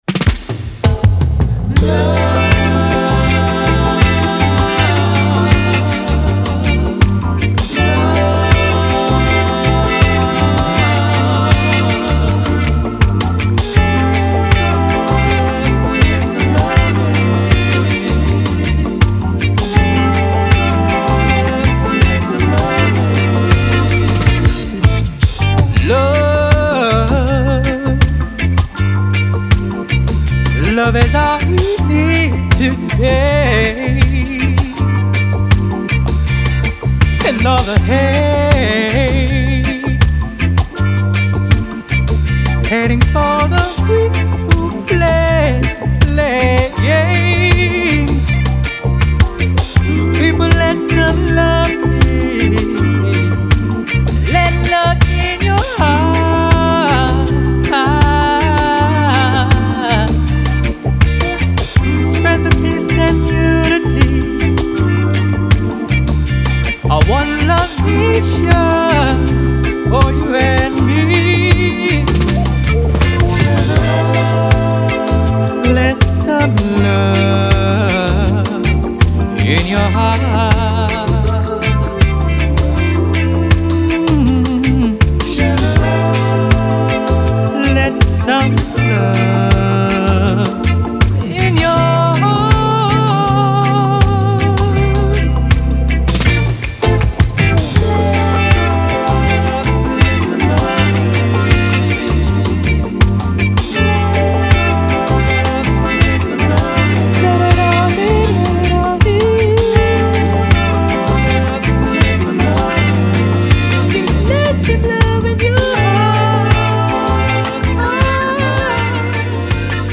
ジャンル(スタイル) REGGAE / SOUL / 初回完全限定盤